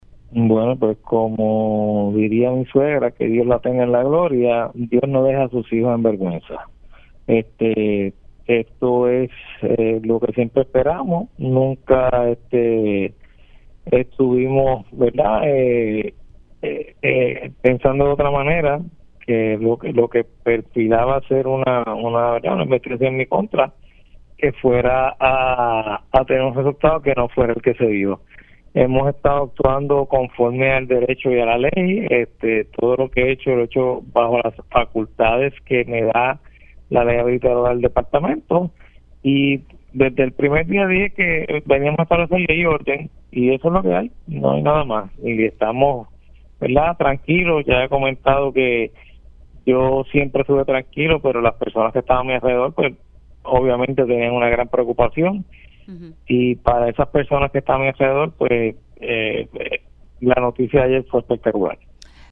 El secretario del Departamento de Recursos Naturales y Ambientales (DRNA), Waldemar Quiles, reaccionó en RADIO ISLA 1320 a la investigación que se llevó a cabo en su contra por una orden ejecutiva que archivó casos relacionados con construcciones ilegales en La Parguera, Lajas.